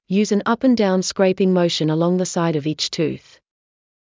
ﾕｰｽﾞ ｱﾝ ｱｯﾌﾟ ｴﾝ ﾀﾞｳﾝ ｽｸﾚｲﾋﾟﾝｸﾞ ﾓｰｼｮﾝ ｱﾛﾝｸﾞ ｻﾞ ｻｲﾄﾞ ｵﾌﾞ ｲｰﾁ ﾄｩｰｽ